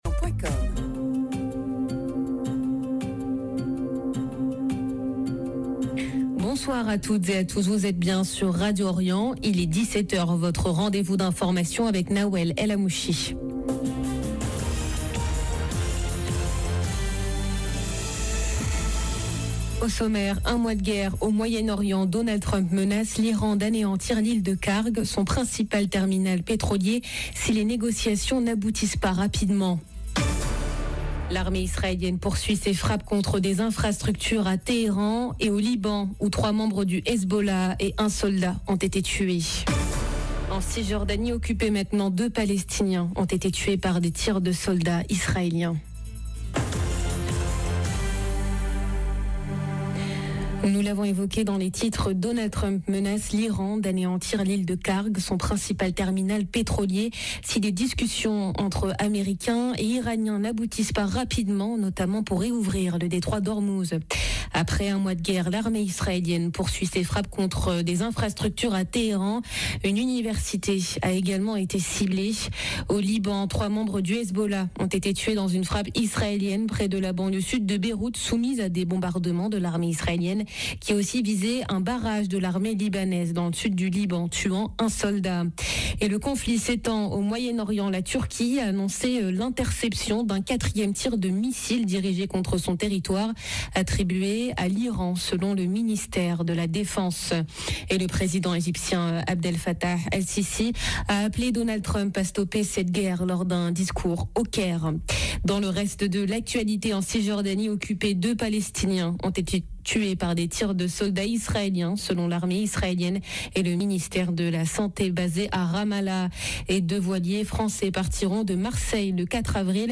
Journal de 17H Au sommaire: Un mois de guerre, au Moyen-Orient, Donald Trump menace l'Iran d'anéantir l'île de Karg, son principal terminal pétrolier, si les négociations n'aboutissent pas rapidement. L'armée israélienne poursuit ses frappes contre des infrastructures à Téhéran et au Liban, où trois membres du Hezbollah et un soldat ont été tués.